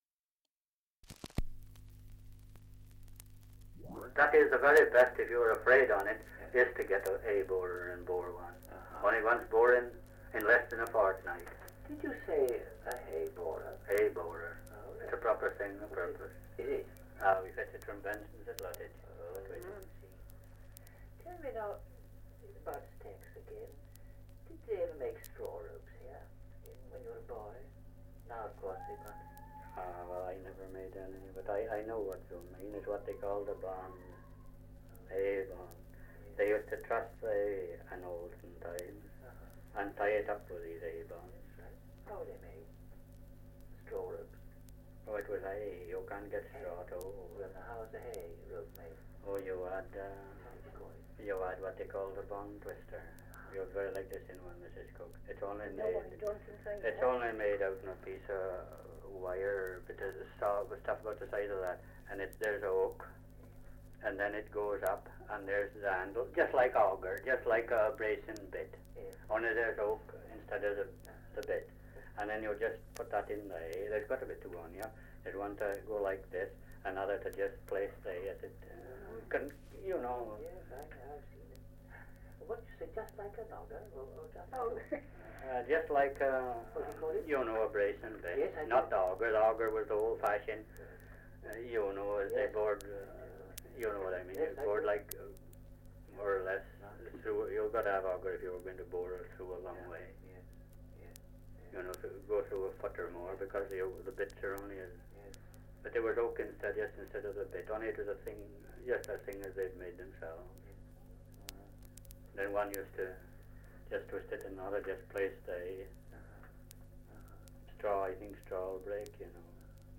Dialect recording in Cardington, Shropshire
78 r.p.m., cellulose nitrate on aluminium